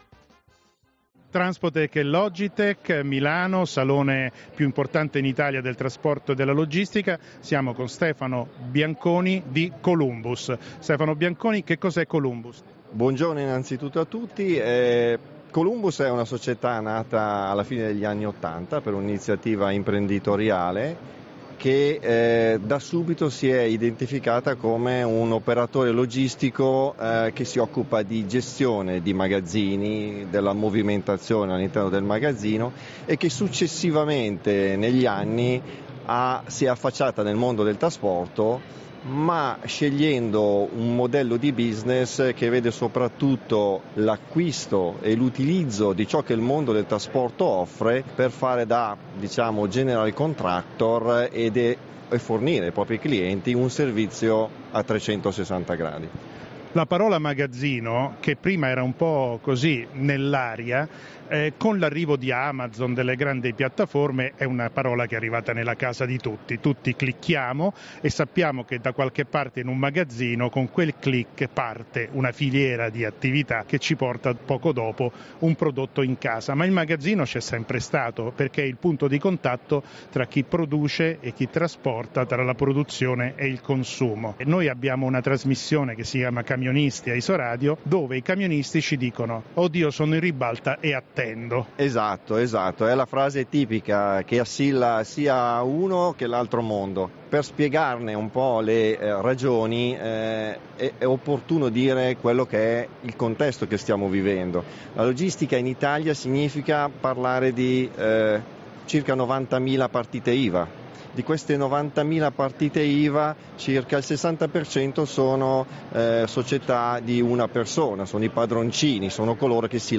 Di seguito potete ascoltare l’intervista integrale.